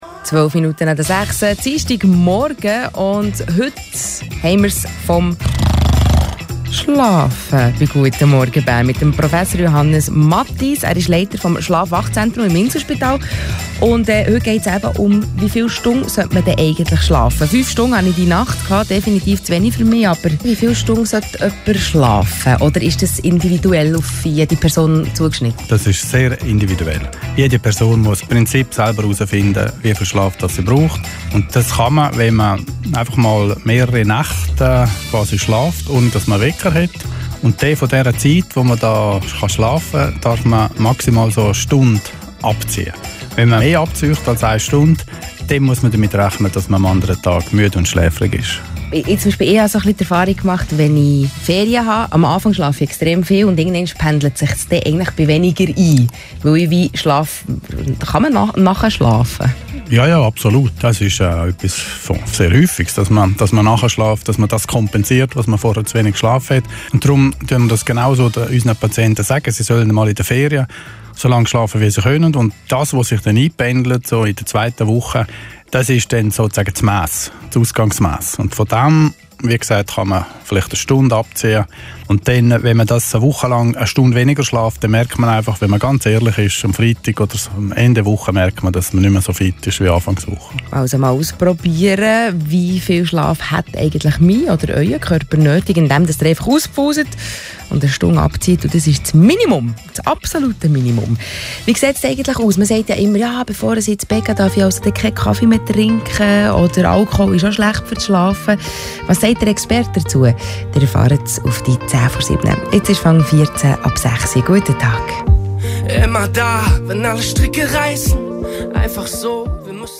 Beiträge vom Radio Bern1, Dienstag, 31. Mai 2016, Morgenshow